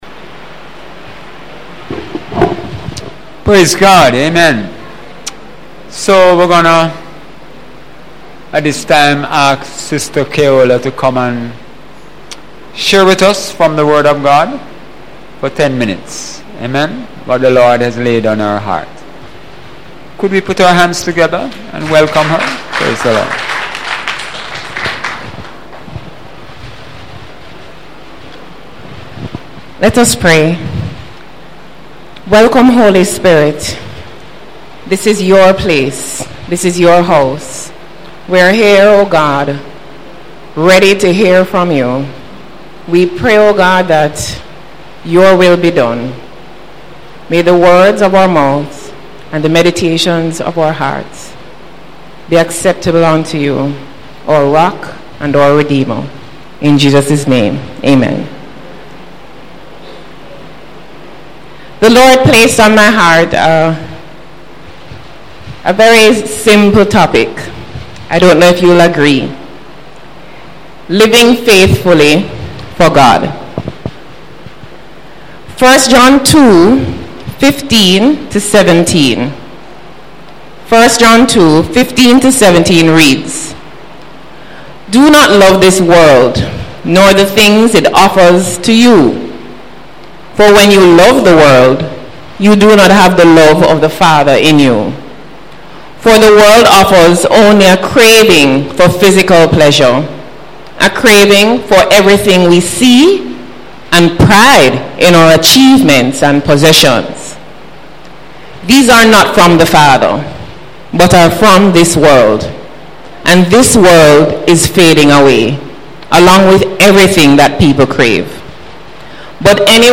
Sunday Service Messsage – April 9, 2017 – Two exhortations